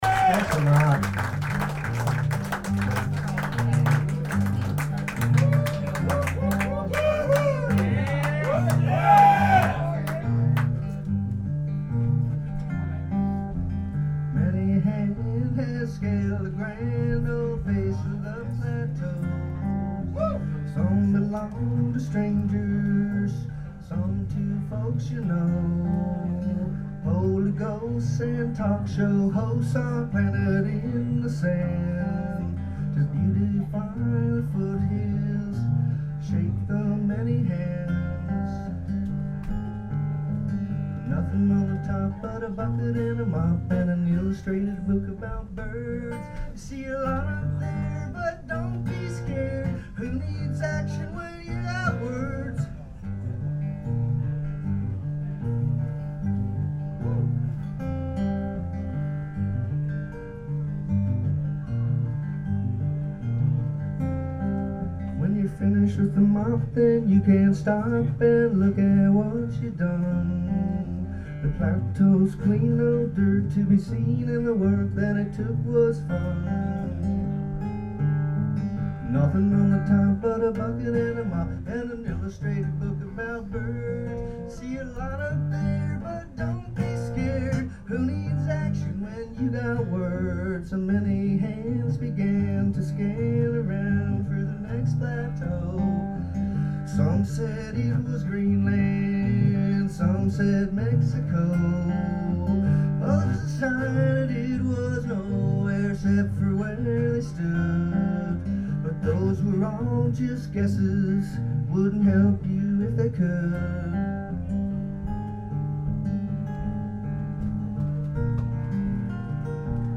here's some recent live tracks recorded a few weeks ago